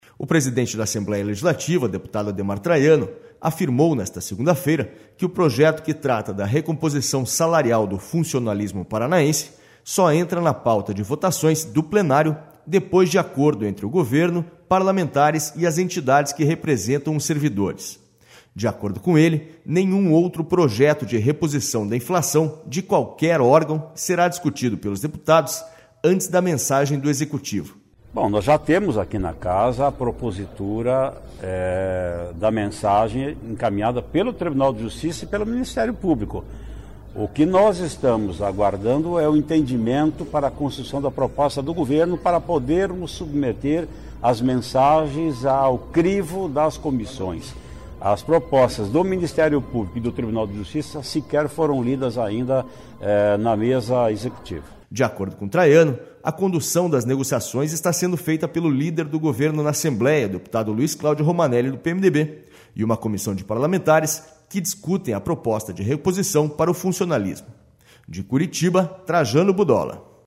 SONORA ADEMAR TRAIANO